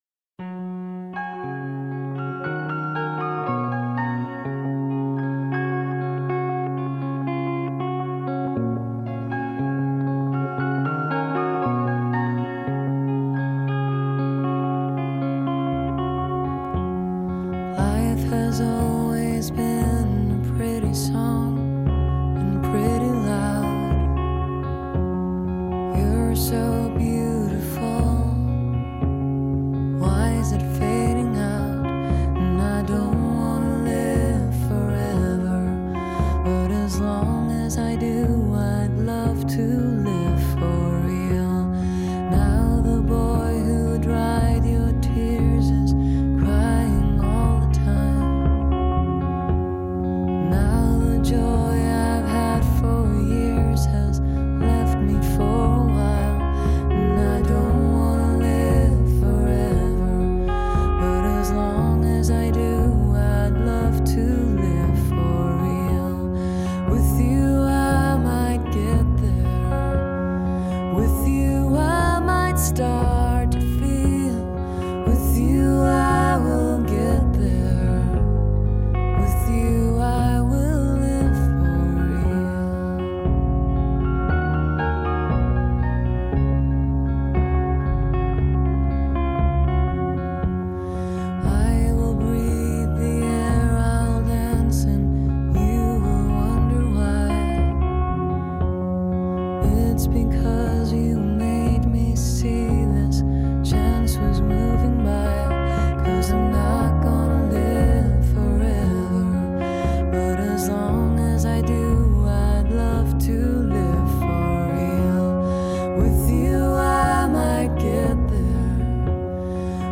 совсем даже не плохой медлячок